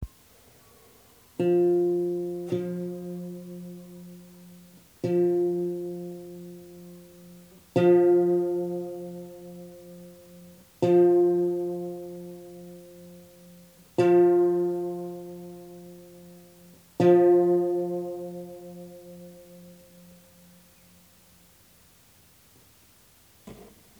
De nuevo he desafinado un unísono para ilustrarlo:
coma-sintc3b3nica-aprox-22-cents.ogg